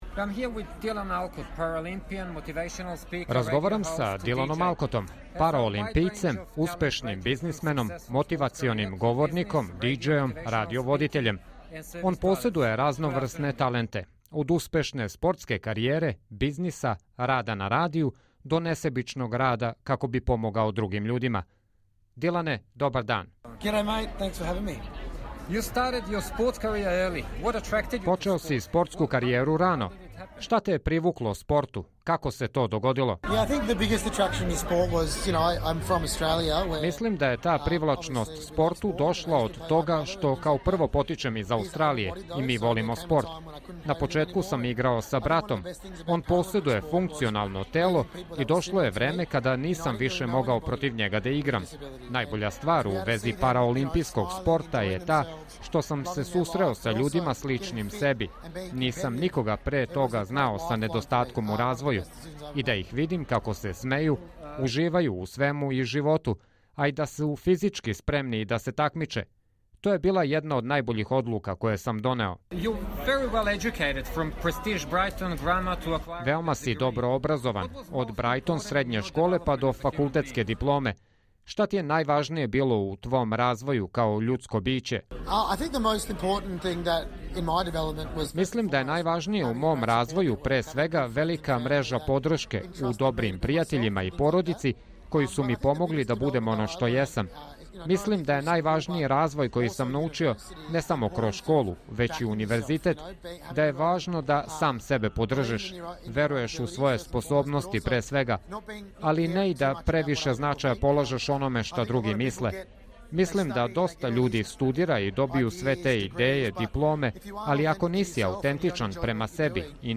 dylan_alcot_intervju_srb_.mp3